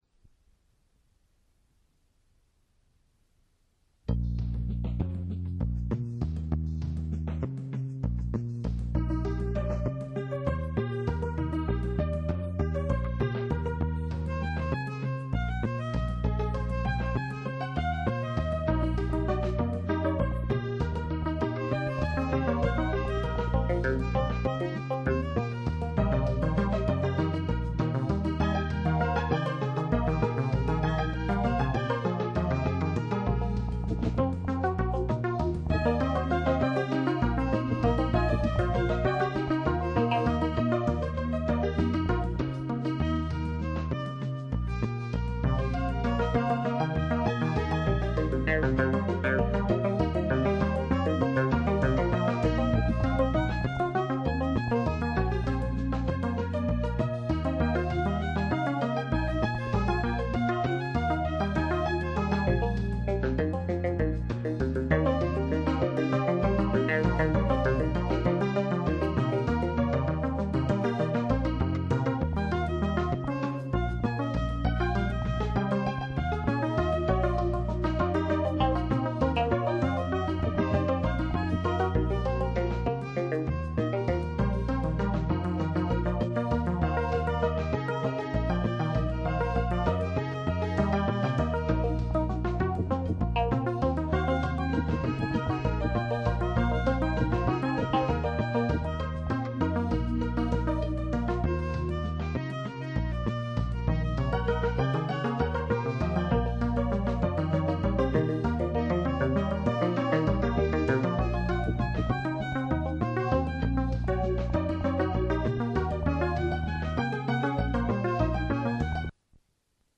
Une petite conclusion toujours avec le Gem